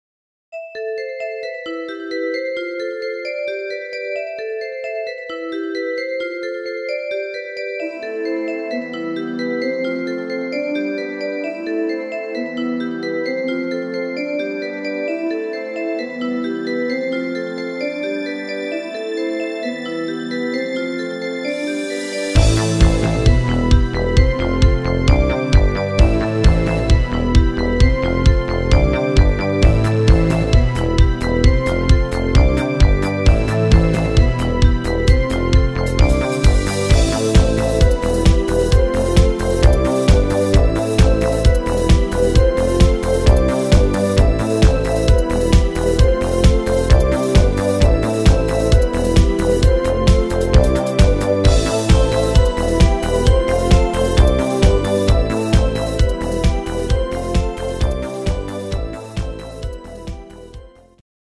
Rhythmus  Dancefloor
Art  Pop, Englisch